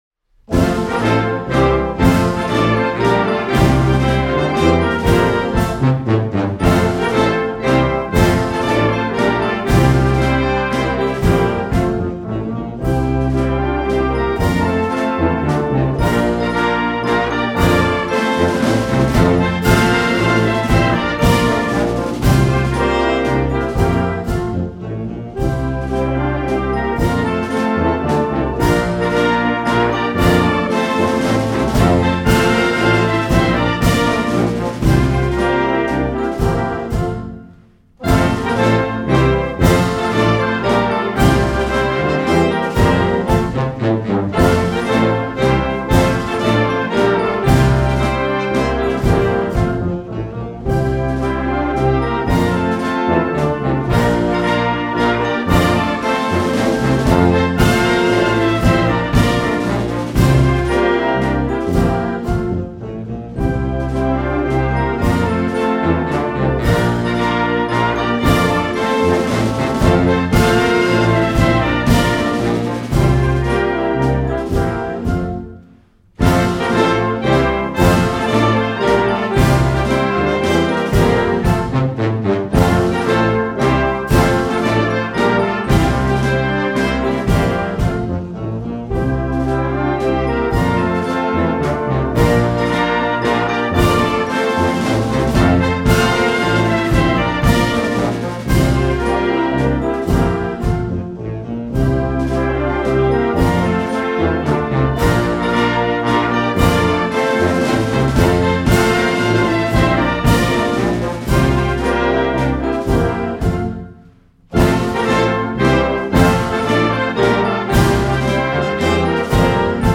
Wersja-instrumentalna-caly-hymn-2-wersja.mp3